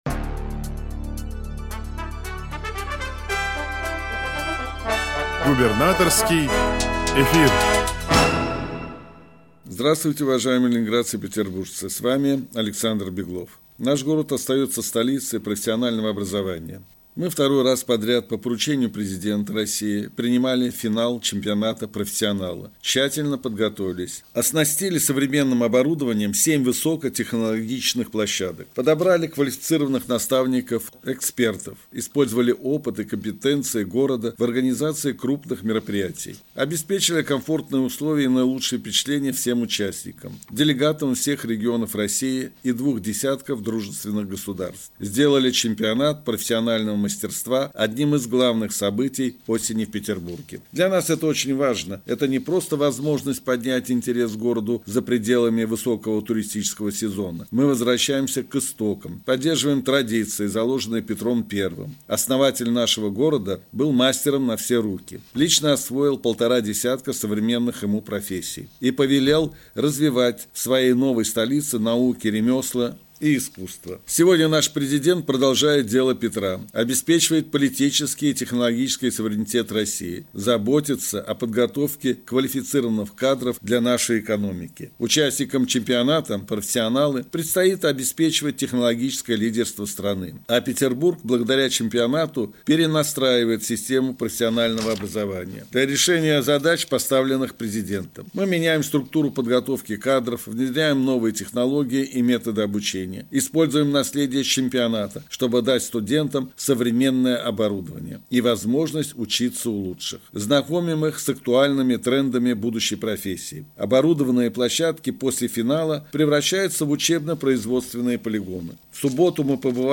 Радиообращение – 2 декабря 2024 года